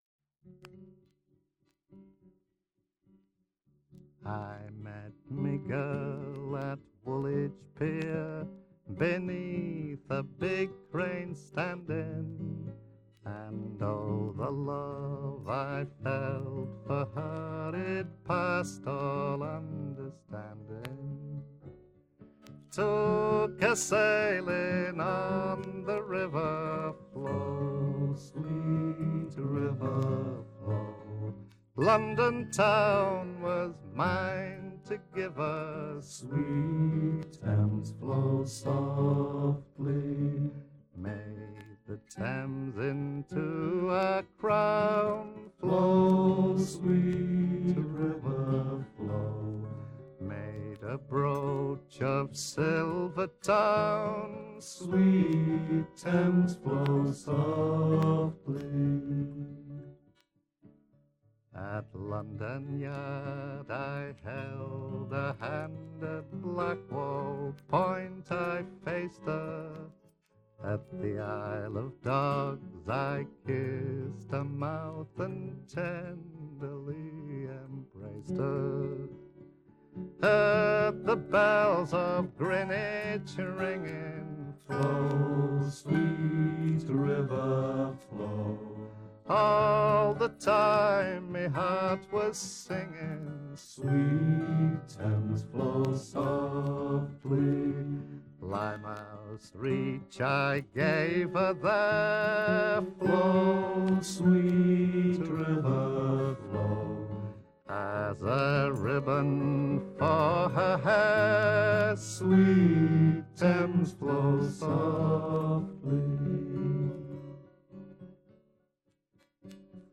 Most of these albums have been converted from vinyl.